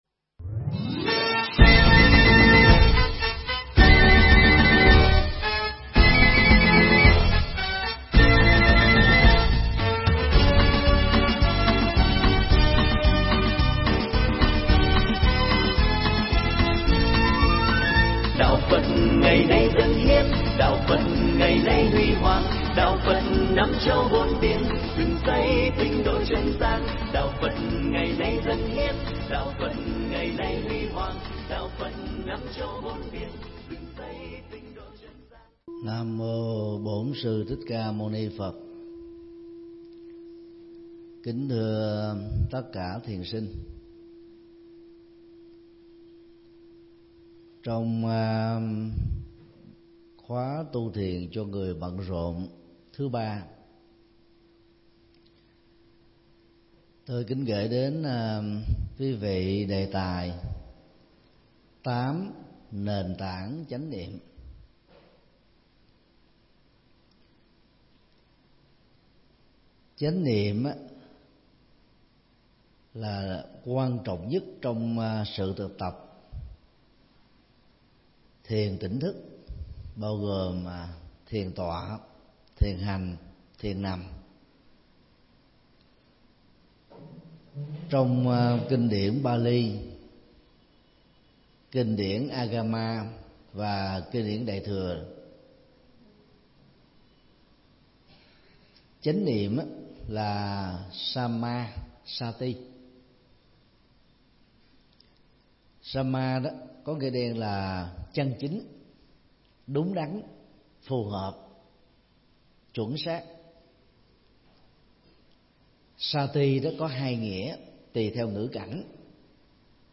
Mp3 Pháp Thoại Tám Nền Tảng Chánh Niệm – Thượng Tọa Thích Nhật Từ giảng trong ngày tu thiền cho người bận rộn kỳ 3 tại chùa Giác Ngộ, ngày 21 tháng 7 năm 2018